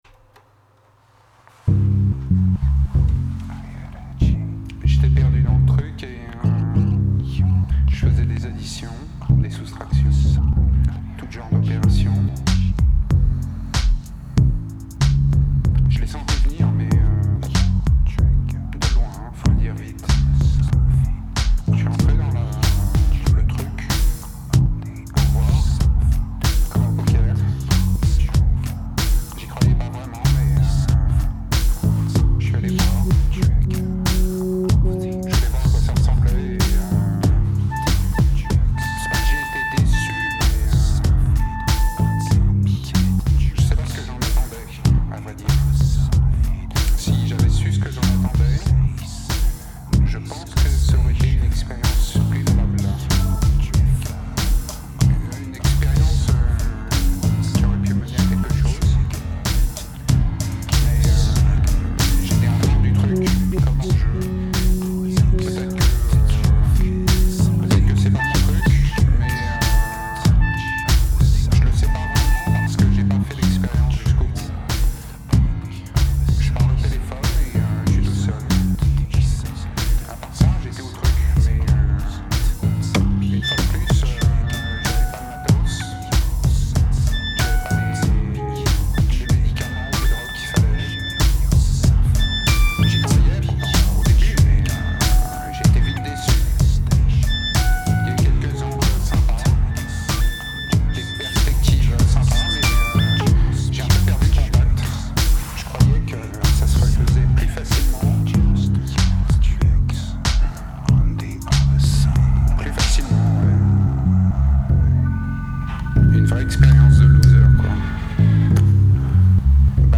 Electro Synthetiseur
Drum Machine Synth
Weird Voice